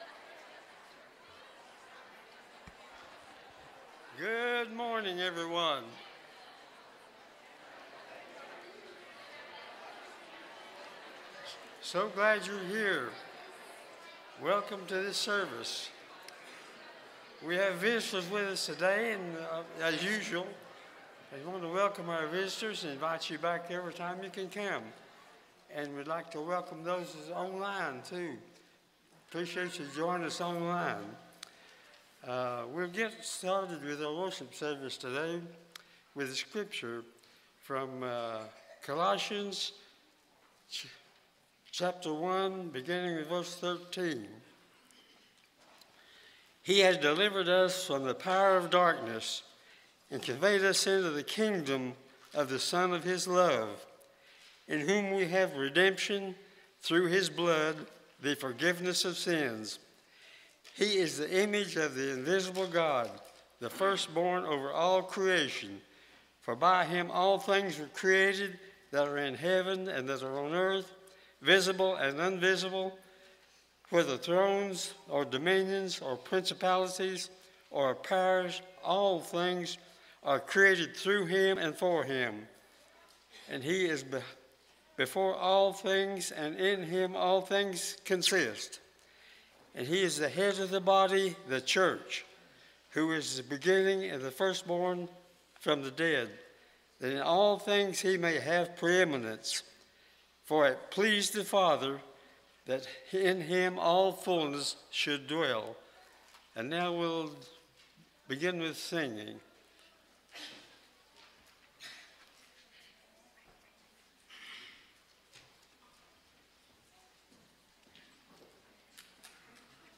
Mark 15:45, English Standard Version Series: Sunday AM Service